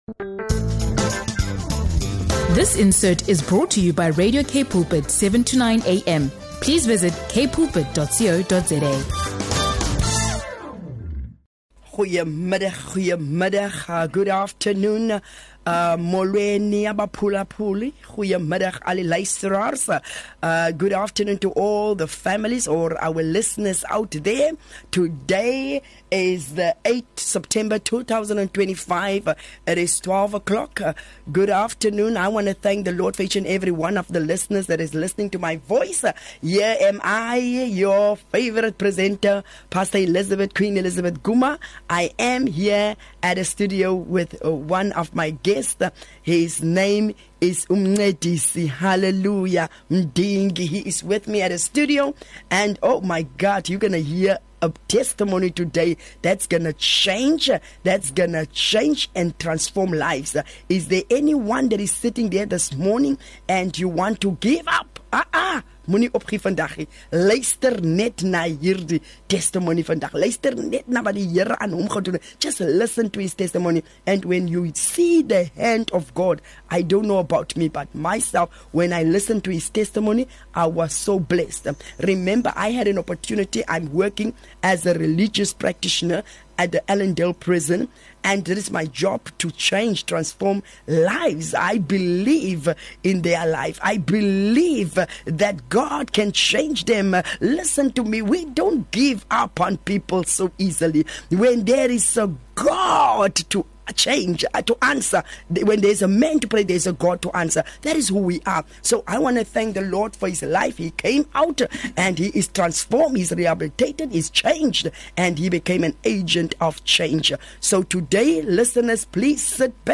Broadcasting from Radio K Pulpit